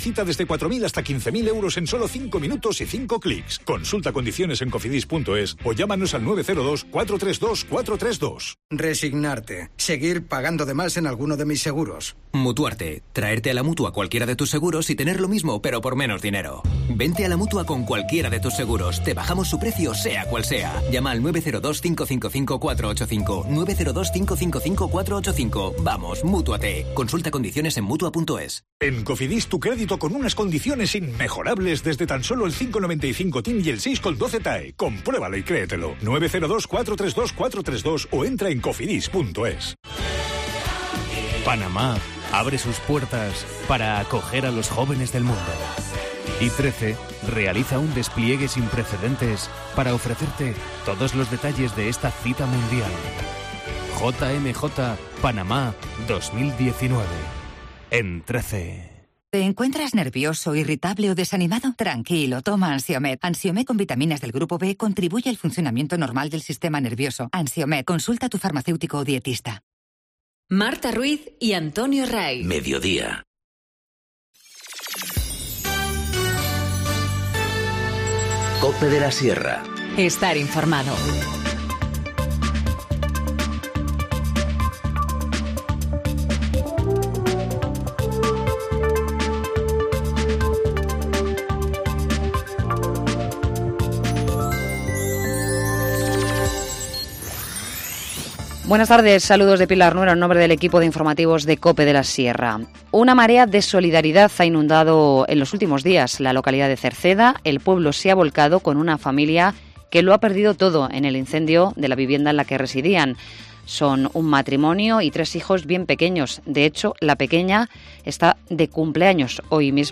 Informativo Mediodía 14 enero- 14:20h